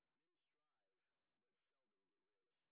sp06_street_snr30.wav